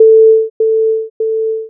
Echoes and attenuation coeficients
Tone A is repeated twice, the first time at one half the original loudness, and the second time at one third.
echo2.wav